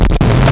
Player_Glitch [89].wav